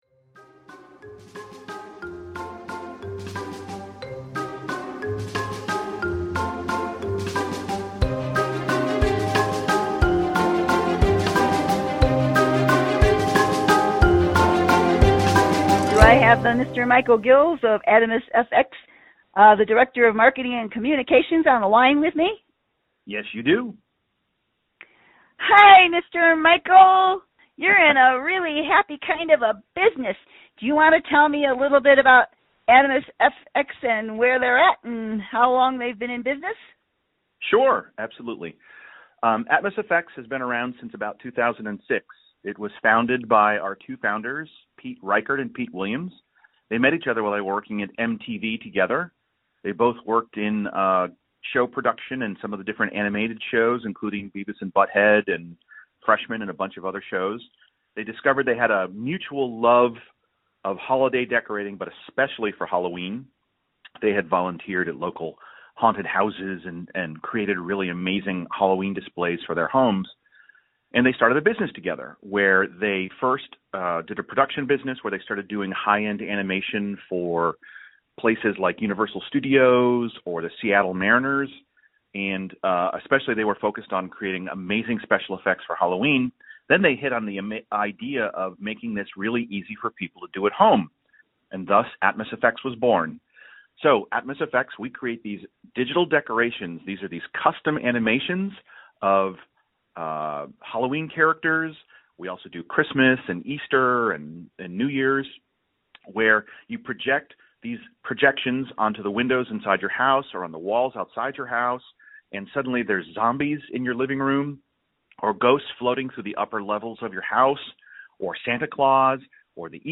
ATMOSFX podcast interview